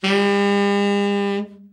Index of /90_sSampleCDs/Giga Samples Collection/Sax/TENOR OVERBL
TENOR OB   2.wav